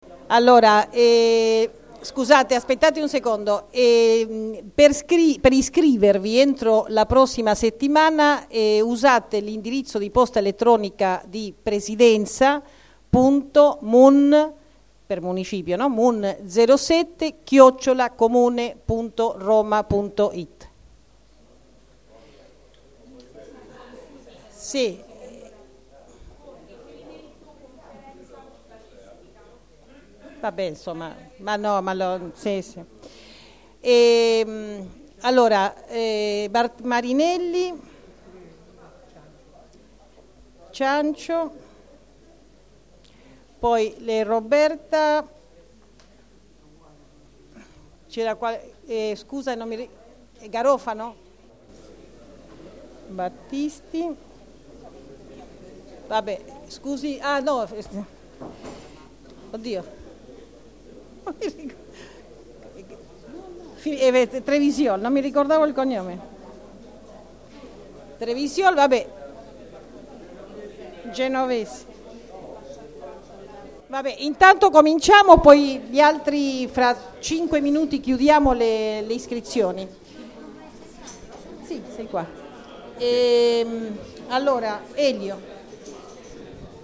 Registrazione integrale dell'incontro svoltosi il 10 giugno 2014 presso la Sala Rossa del Municipio in Piazza di Cinecittà, 11
04-fantino   Susana Ana Fantino, Presidente del Municipio VII